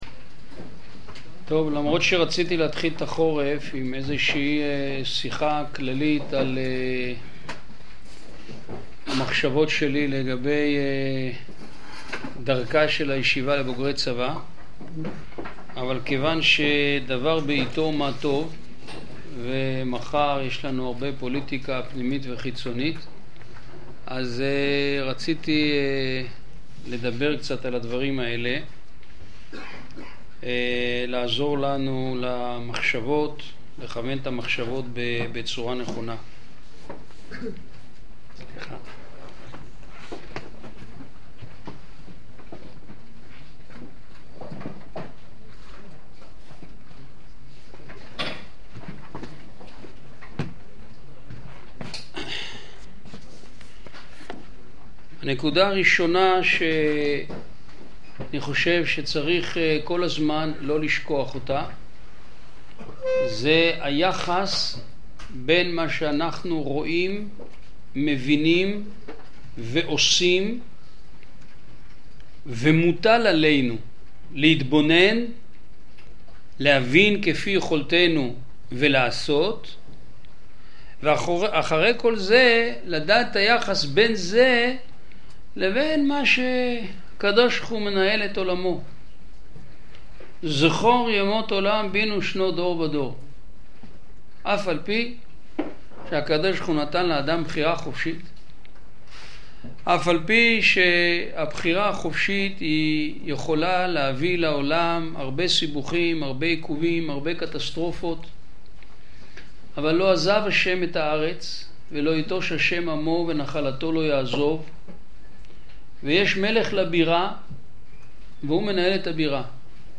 הפוליטיקה הישראלית | שיעור כללי - בני דוד - עלי